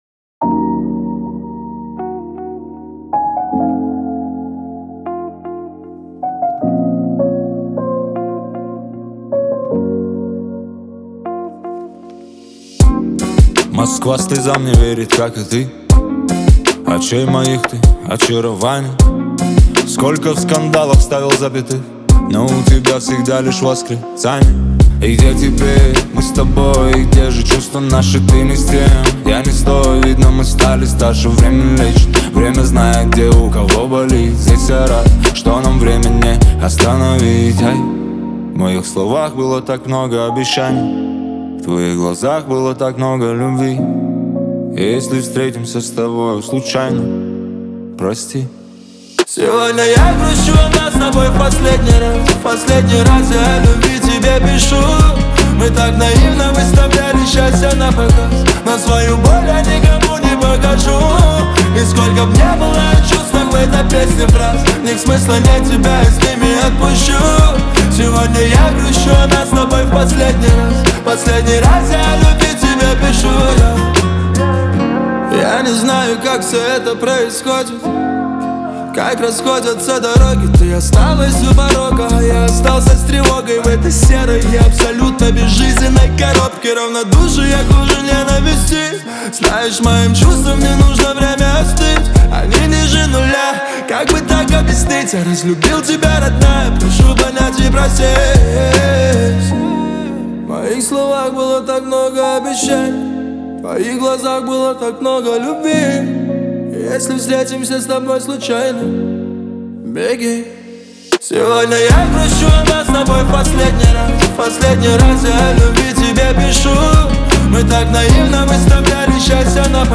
эмоциональный трек в жанре поп